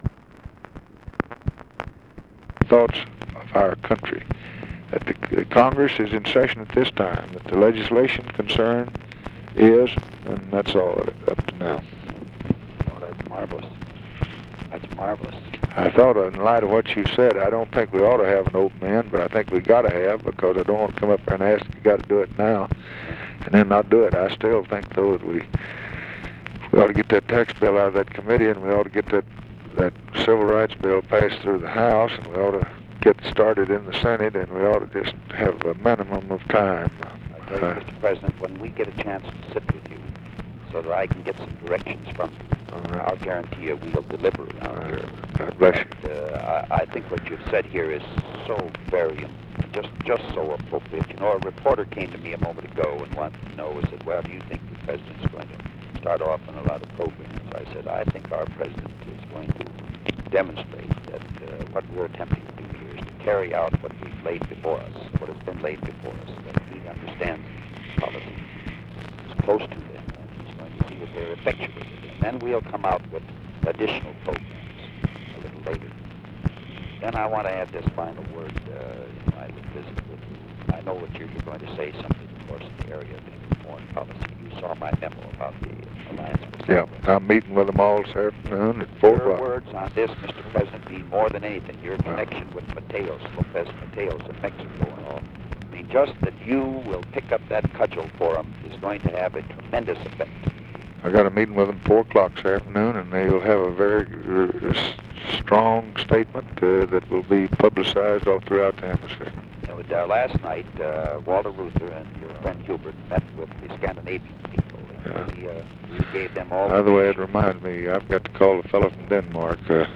Conversation with HUBERT HUMPHREY, November 26, 1963
Secret White House Tapes